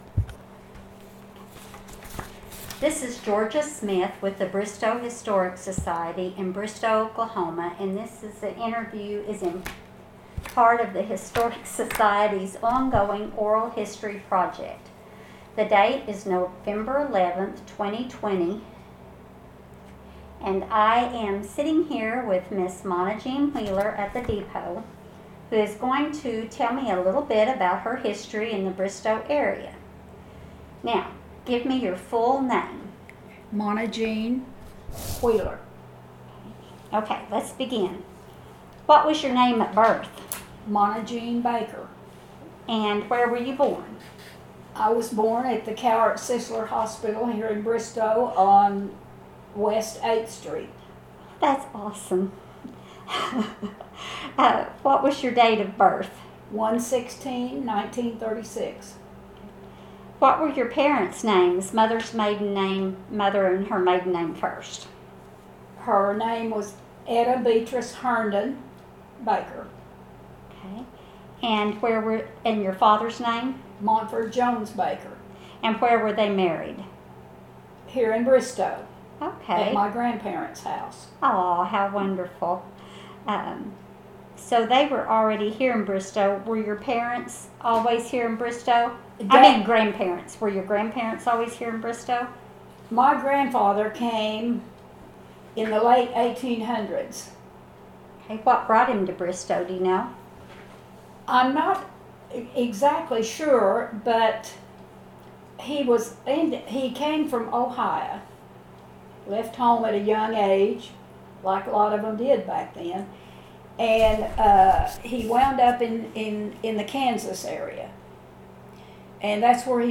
Preface: The following oral history testimony is the result of a cassette tape interview and is part of the Bristow Historical Society, Inc.'s collection of oral histories.